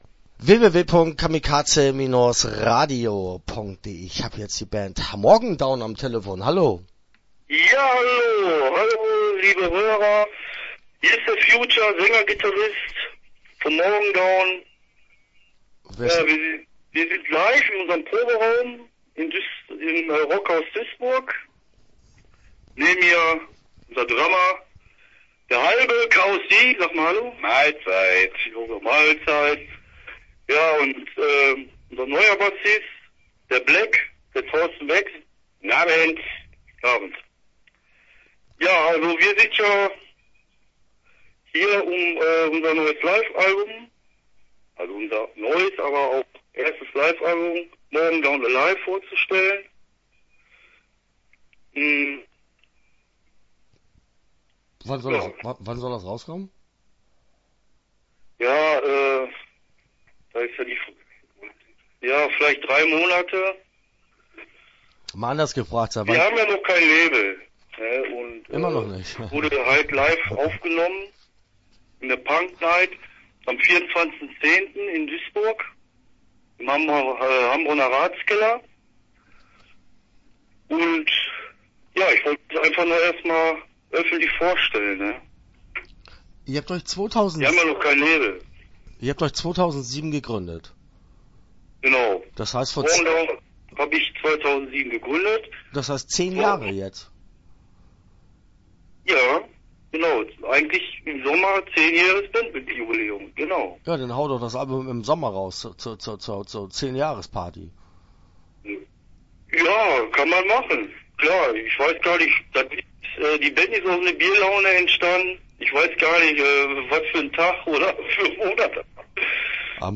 Start » Interviews » Morgendown